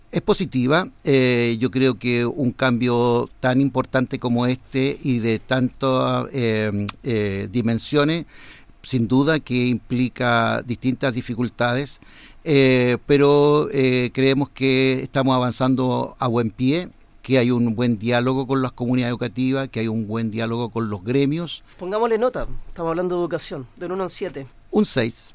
Entrevista a director ejecutivo de SLEP Valdivia